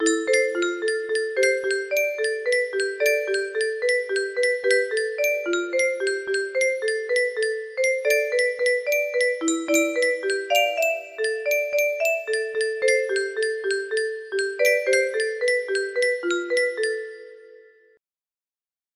Unknown Artist - Untitled music box melody
Grand Illusions 30 music boxes More